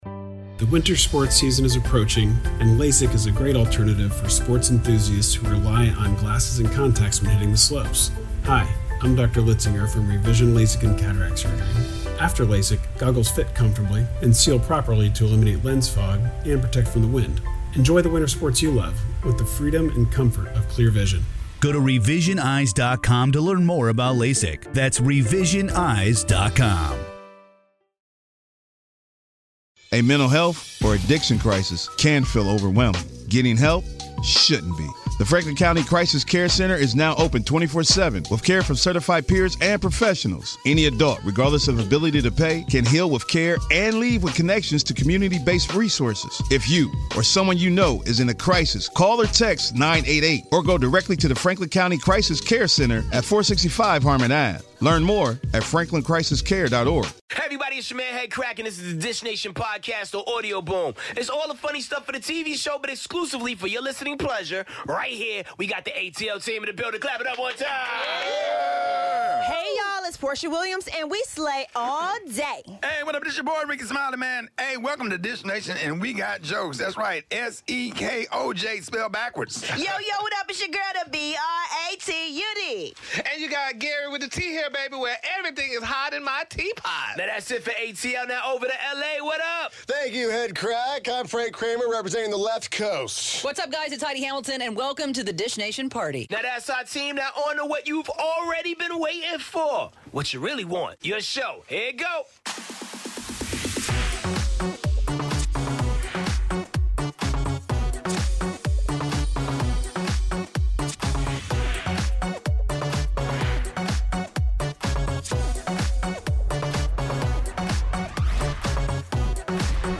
Guest host: Eva Marcille. 'Real Housewives' stars Teresa Guidice's shocking transformation and Kim Zolciak's new song.